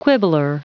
Prononciation du mot quibbler en anglais (fichier audio)
Prononciation du mot : quibbler